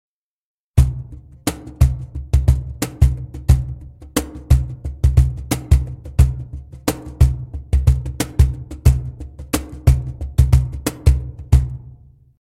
●両面叩きの低音志向カホンです。ヘッドはバーチ合板で、柔らかめのタッチ、サイドホール独特の圧倒的な低音が楽しめます。バズワイヤー面のサウンドはあくまでボトムエンドは低く、高域はしっかり伸びたワイドレンジなカホンです。反対面のサウンドは木質系のウオームな響きでソロ、アクセントに充分な音量で臨めます。